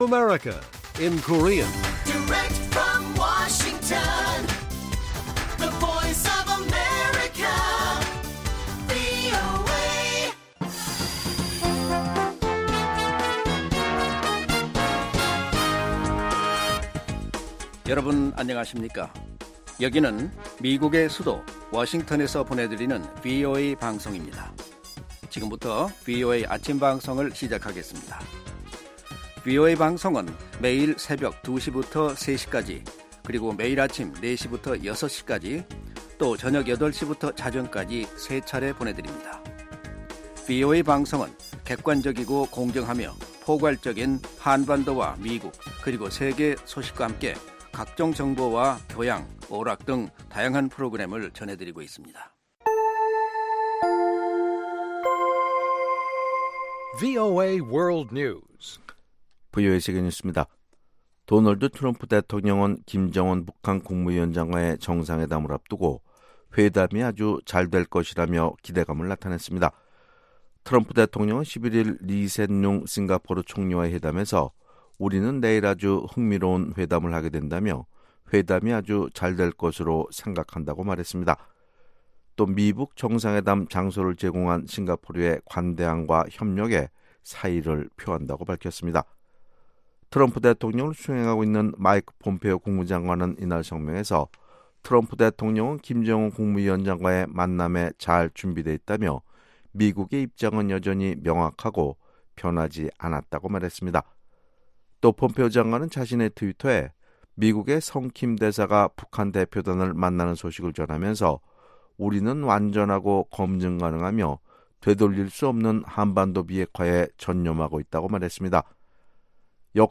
생방송 여기는 워싱턴입니다 6/12 아침
세계 뉴스와 함께 미국의 모든 것을 소개하는 '생방송 여기는 워싱턴입니다', 2018년 6월 12일 아침 방송입니다. ‘지구촌 오늘’ 에서는 주요7개국(G7) 정상회의가 미국의 반대로 공동성명을 채택하지 못했다는 소식, ‘아메리카 나우’에서는 전임 오바마 행정부 때 도입됐던 망 중립성 규정이 11부로 효력이 중단됐다는 이야기 전해드립니다. ' 구석구석 미국 이야기'에서는 거리의 관객을 찾아가는 ‘콘서트 트럭’ 이야기를 소개합니다.